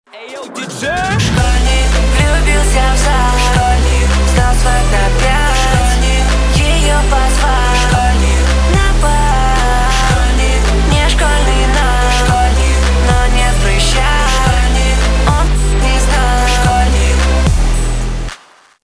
pop rock
рэп